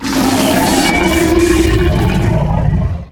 bighurt2.ogg